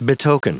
Prononciation du mot betoken en anglais (fichier audio)
Prononciation du mot : betoken